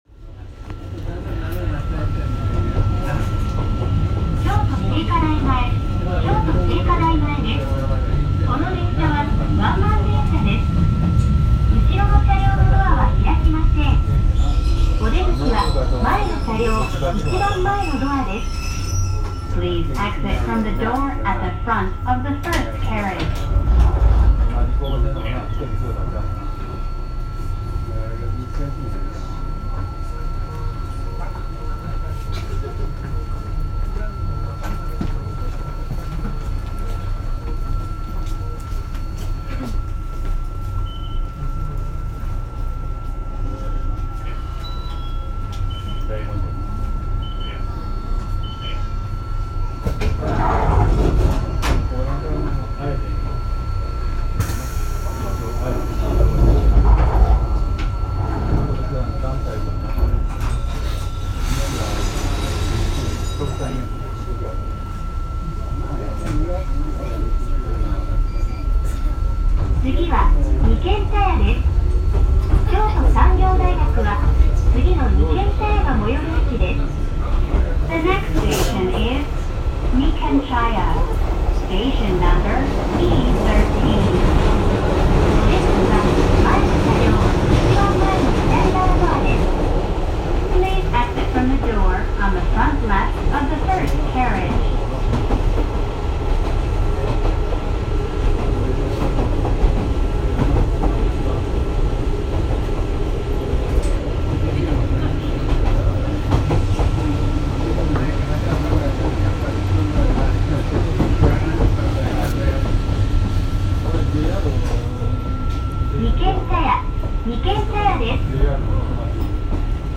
Aboard the train to Mount Kurama
Heading north on the train to Mount Kurama - a stunning view of trees turning red in the autumn, and the peaceful suburbs of Kyoto, far from the bustle of the city.
A tranquil, scenic train journey - what is life like in the tranquil outskirts of Kyoto close to the mountains?